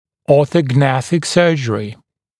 [ˌɔːθəˈgnæθɪk ‘sɜːʤ(ə)rɪ][ˌо:сэ’гнэсик ‘сё:дж(э)ри]ортогнатическая хирургия